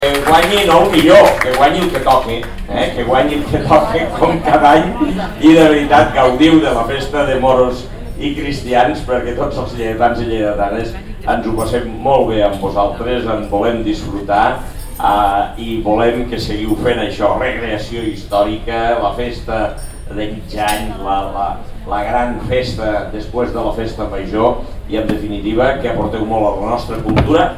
Tall de veu de l'alcalde, Àngel Ros, sobre la celebració del Mig Any Fester de la Festa de Moros i Cristians de Lleida (431.0 KB) Fotografia 1 amb major resolució (2.4 MB) Fotografia 2 amb major resolució (2.7 MB)
tall-de-veu-de-lalcalde-angel-ros-sobre-la-celebracio-del-mig-any-fester-de-la-festa-de-moros-i-cristians-de-lleida